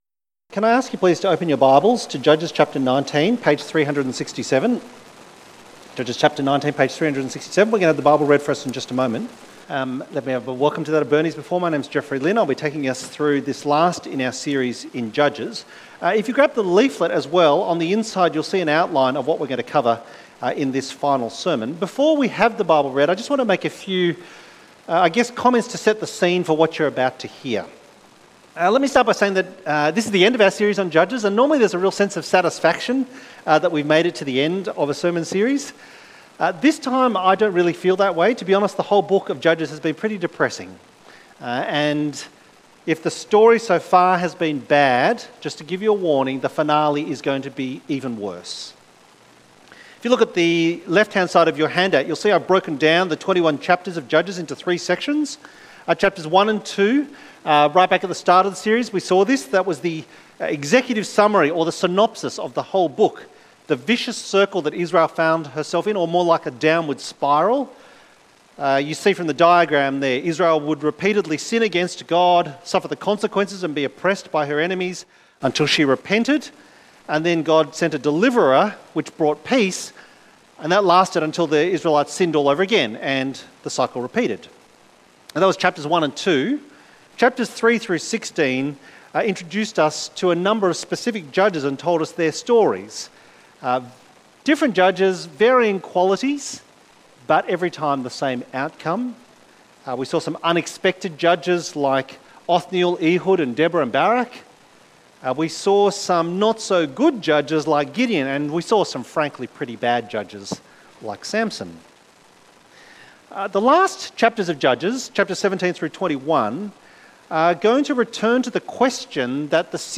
In Those Days Israel Had No King Sermon outline Share this page